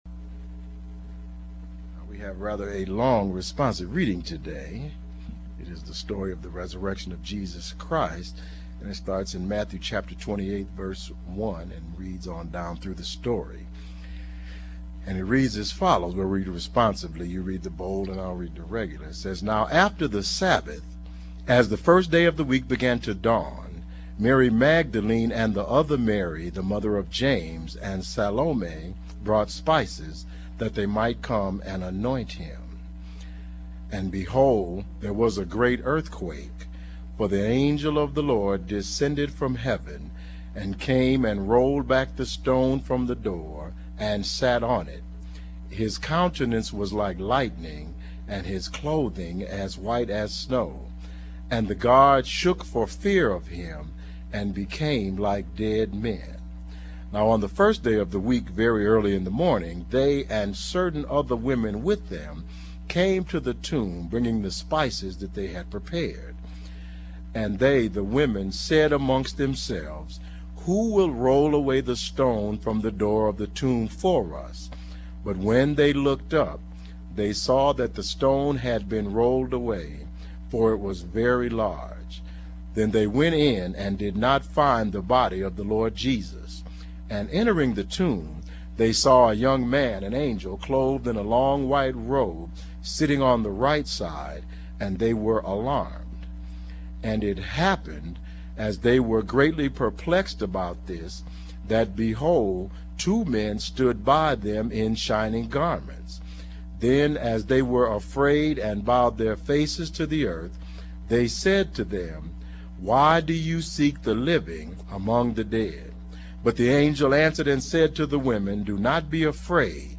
Audio Download: Click to download Audio (mp3) Additional Downloads: Click to download Sermon Text (pdf) Content Feeds Use the links below to subscribe to our regularly produced audio and video content.